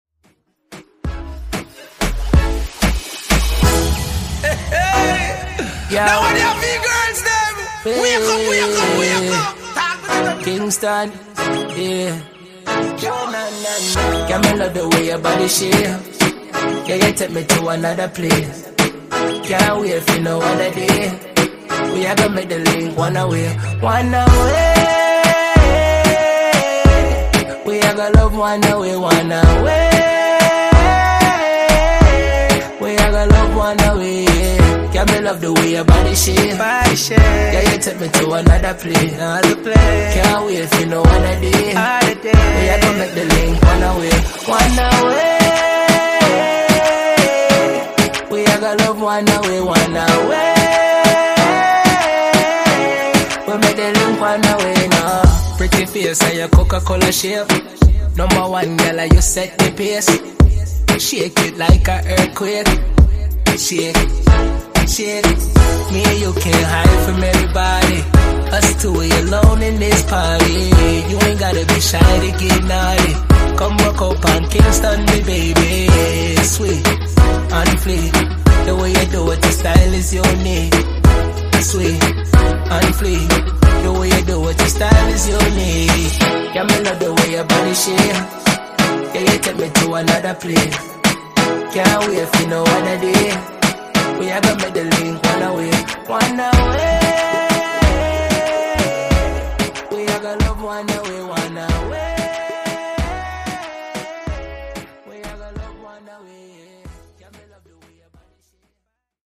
Genres: R & B , RE-DRUM
Clean BPM: 77 Time